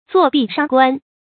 注音：ㄗㄨㄛˋ ㄅㄧˋ ㄕㄤˋ ㄍㄨㄢ
作壁上觀的讀法